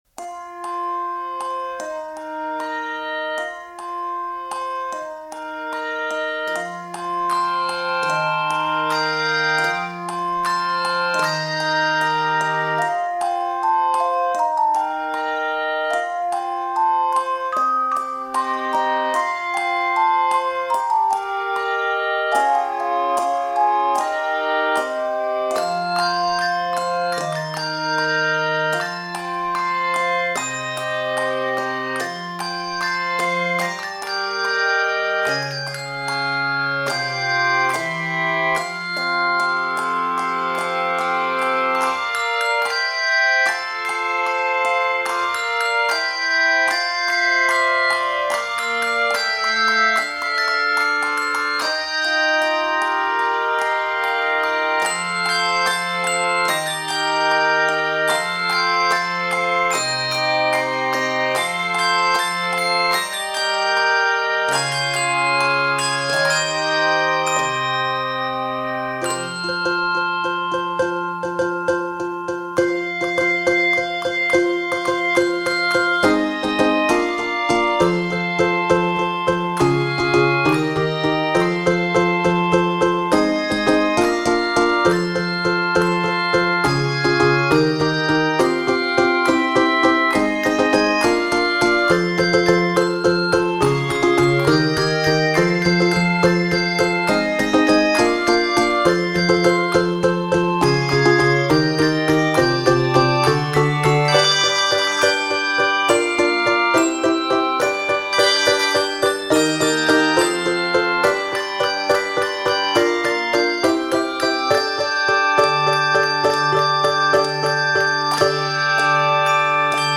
rich chromatic passages and melodic counterpoint